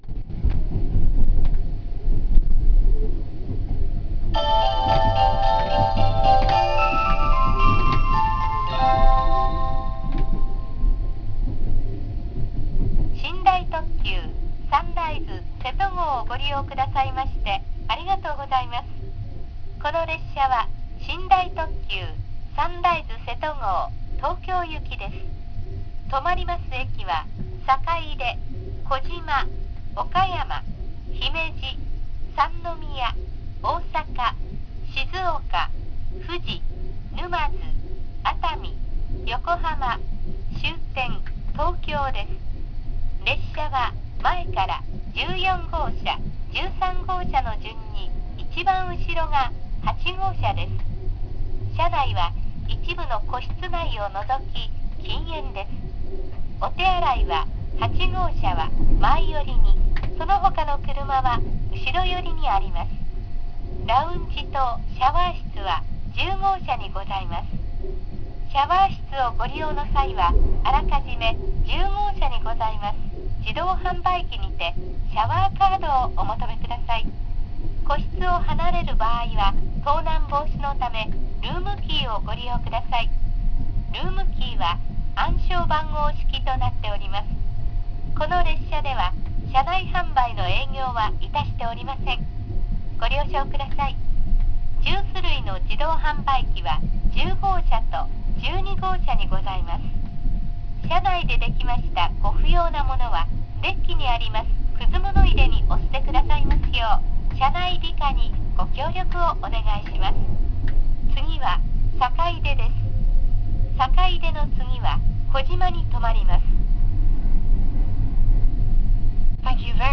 ・285系車内チャイム・放送（更新後）
【始終着用】＋自動放送(高松発車後)（2分45秒：901KB）
いつ頃かはわかりませんが、その285系の車内チャイムが音質の良い物に交換され、同じチャイムを使用している281系同様、始終着専用のチャイムも用意され、自動放送も搭載されましたました。